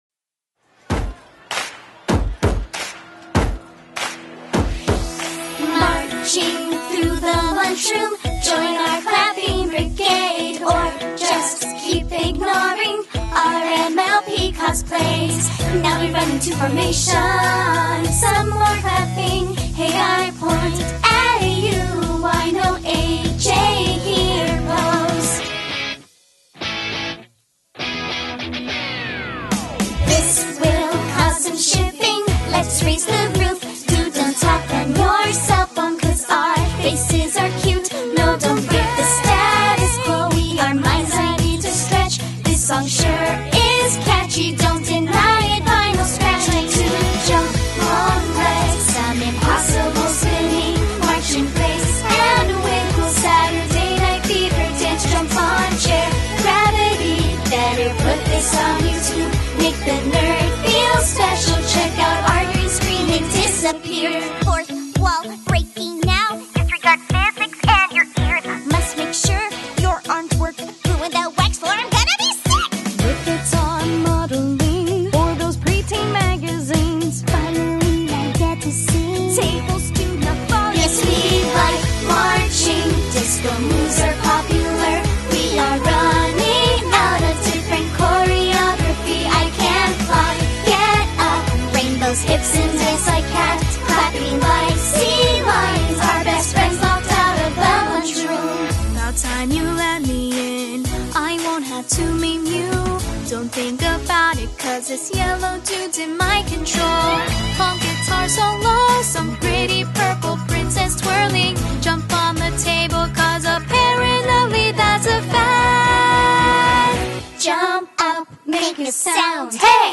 Heck, do you hear those beautiful harmonies?